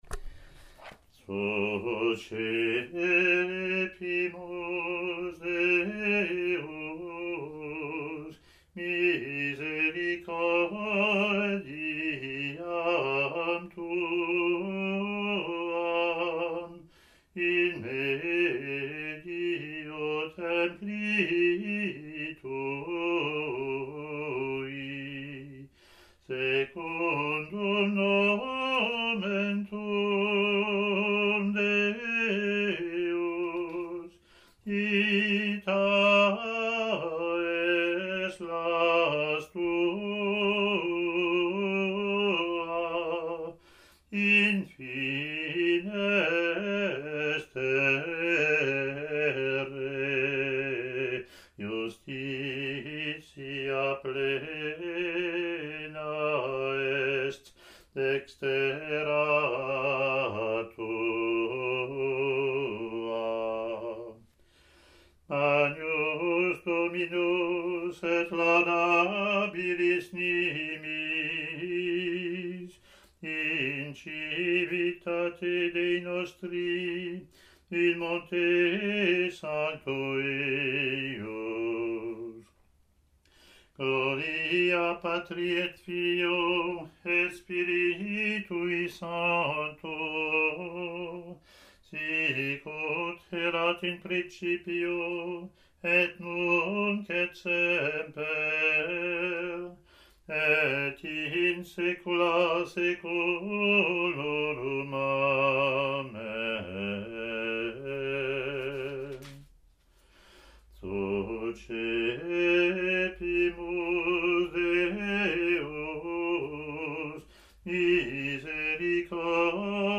Latin antiphon and verse)